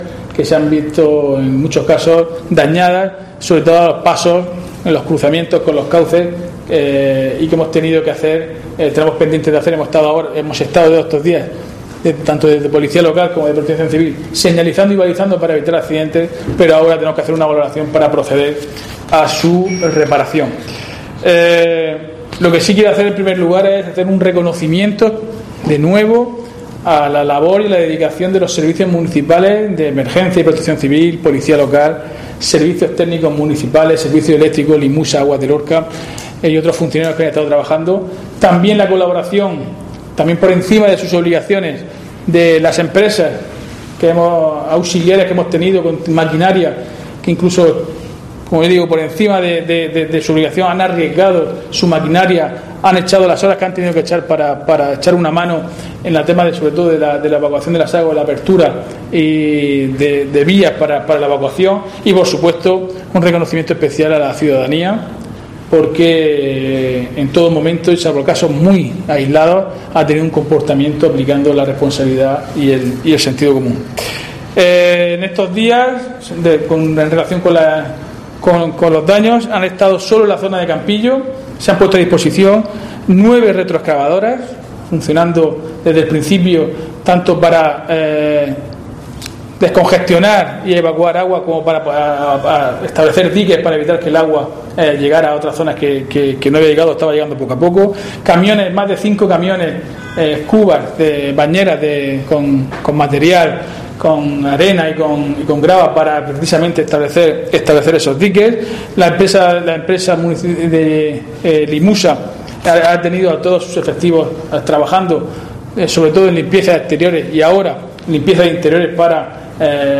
Diego José Mateos, alcalde de Lorca, valoración de daños temporal